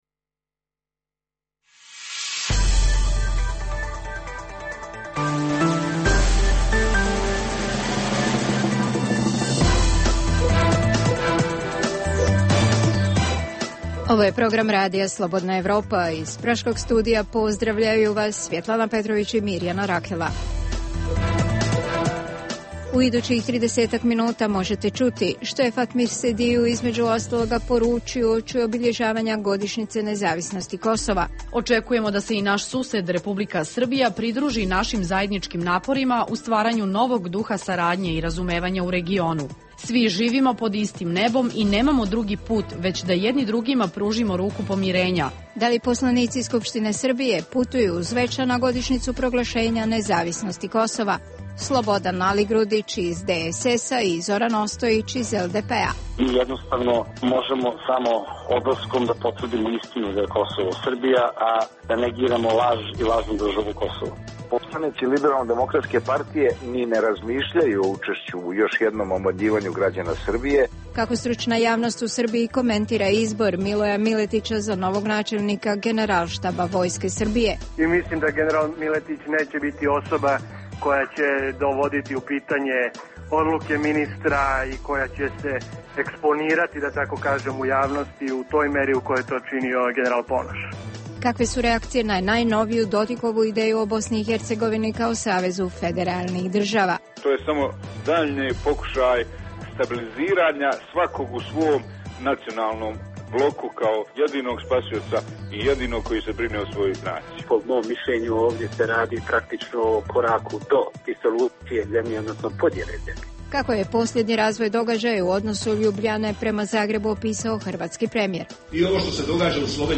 U regionalnom programu emisije istražujemo kako se u BiH reagira na nove "ideje" Milorada Dodika o ustroju države, kako se Kosovo priprema za prvu godišnjicu nezavisnosti, da li će srpski parlamentarci 17. februara biti na Kosovu, kakva je perspektiva hrvatsko-slovenskih odnosa. Objavljujemo i razgovor s predsjedavajućim Predsjedništva BiH Nebojšom Radmanovićem, tražimo odgovor zašto je Rasim Ljajić uvjeren da je izvjesno hapšenje Ratka Mladića, te tko je Miloje Miletić, novi načelnik generalštaba Vojske Srbije.